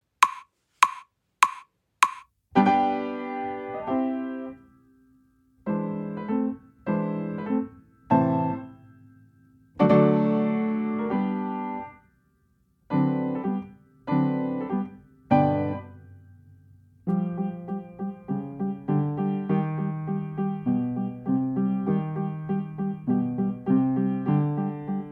akompaniamentu pianina
Nagrania dokonane na pianinie Yamaha P2, strój 440Hz
piano